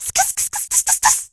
emz_hurt_vo_02.ogg